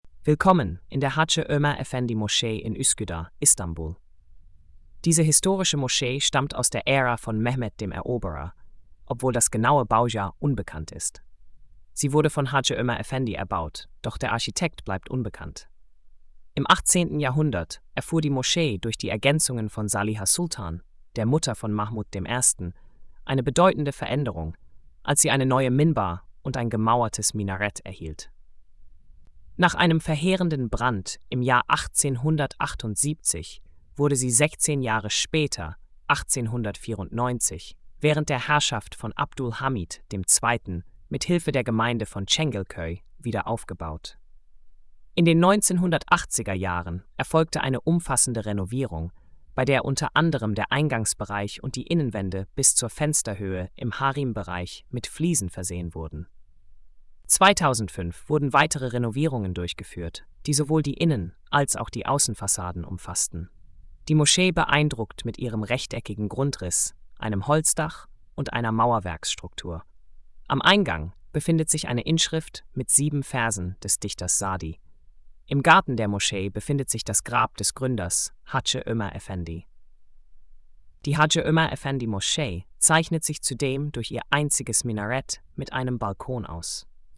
Audio Erzählung